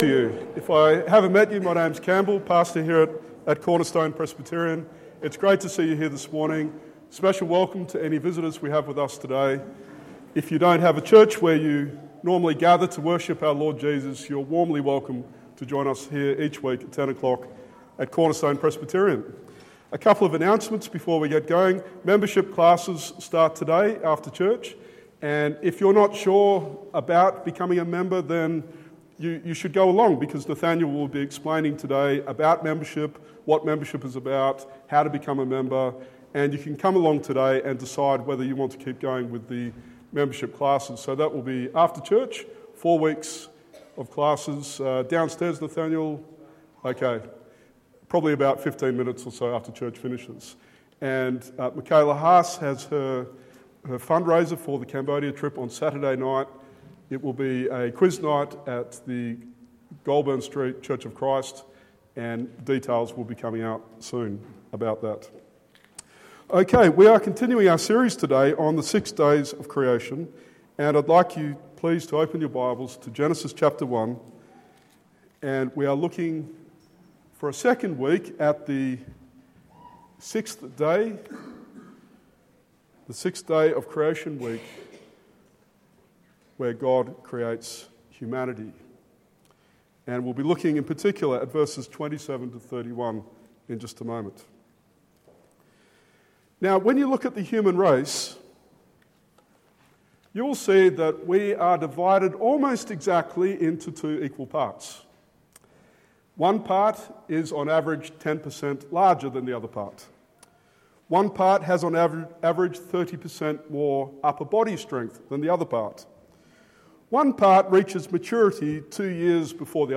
Genesis 1:2-31 Sermon